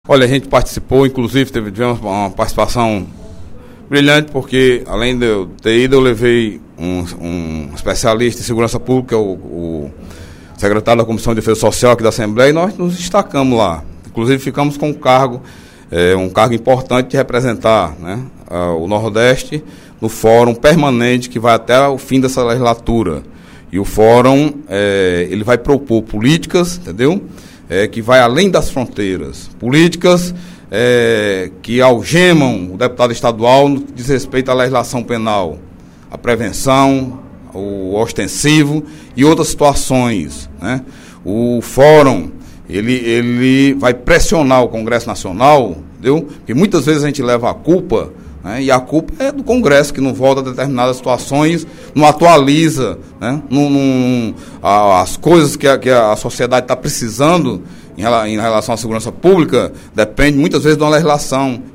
O deputado Delegado Cavalcante (PDT) fez pronunciamento, nesta quarta-feira (27/06), para anunciar que foi escolhido o representante do Nordeste no Fórum Permanente de Segurança Pública.